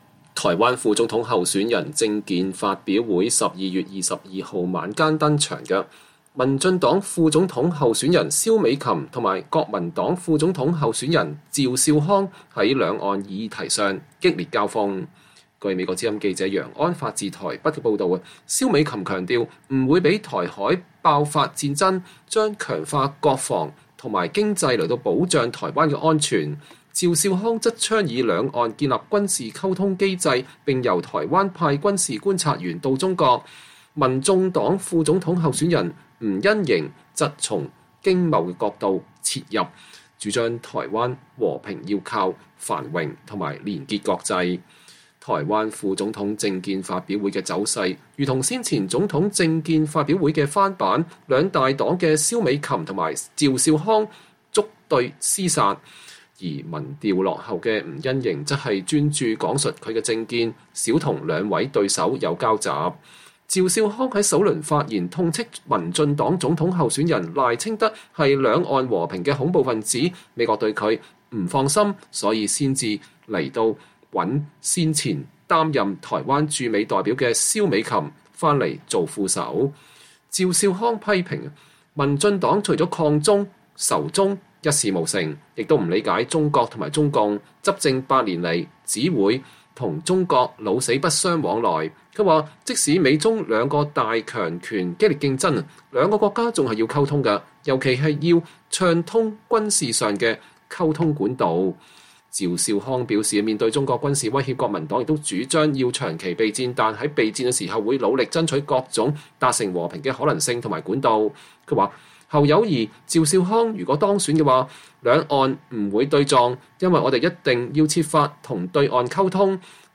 台灣副總統候選人政見發表會：三黨就兩岸和平之道交鋒
台灣副總統政見發表會，民進黨蕭美琴（左）、國民黨趙少康（中）言辭激烈交鋒，民眾黨吳欣盈（右）專心闡述政見。